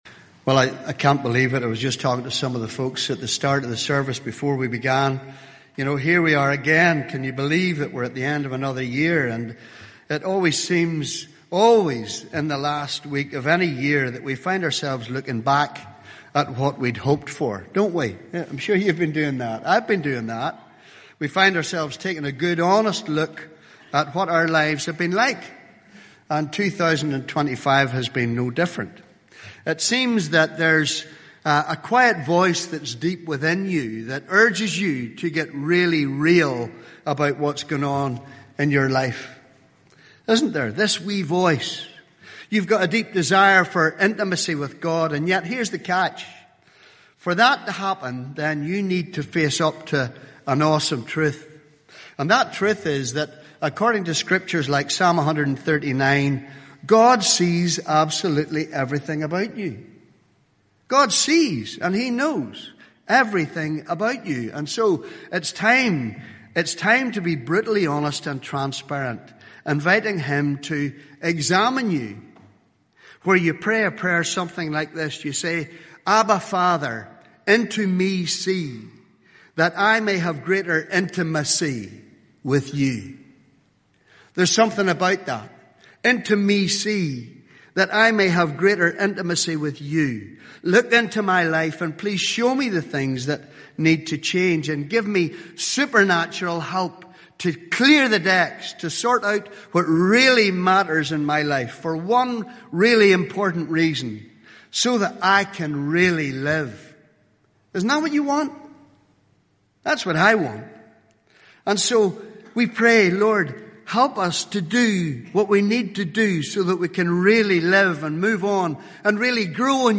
Summary: As we reflect on the past year, many of us feel the weight of unmet aspirations and struggles. In this message, we explore the idea that true transformation begins when we acknowledge our limitations and invite God into our brokenness.